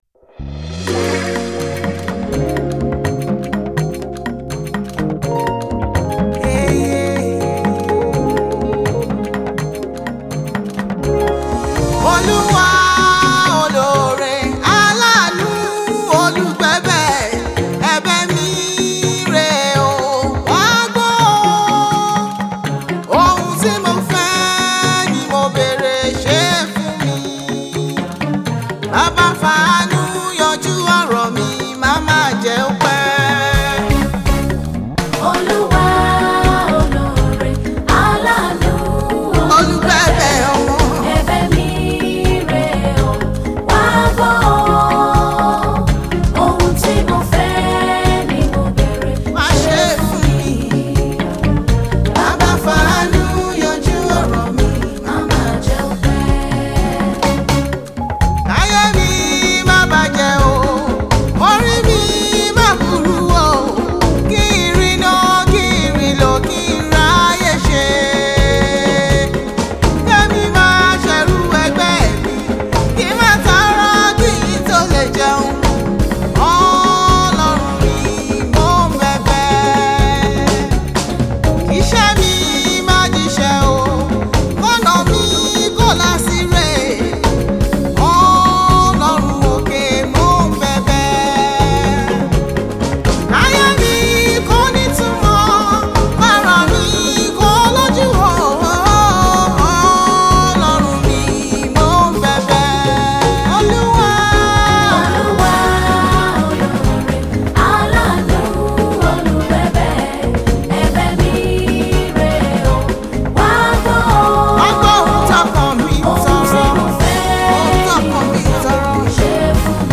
Nigerian gospel artiste
gospel singer-songwriter